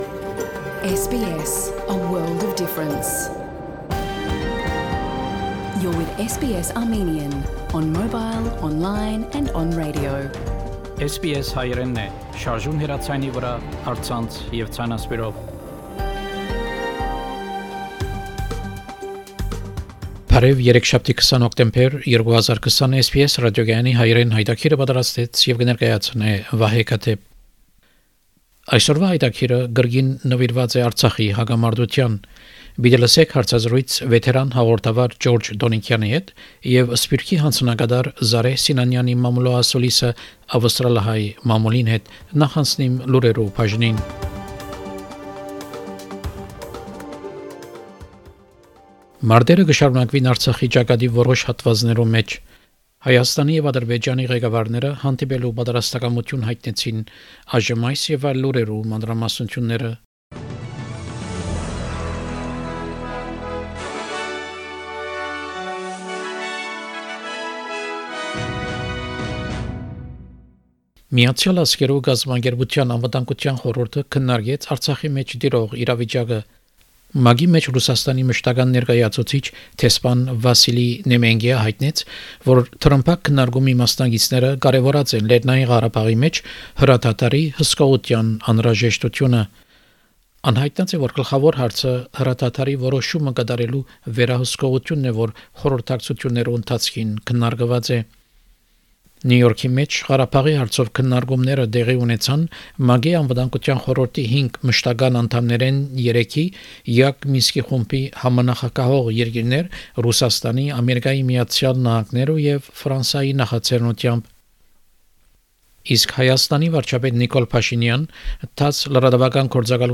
SBS Armenian news bulletin – 20 October 2020
SBS Armenian news bulletin from 20 October 2020 program.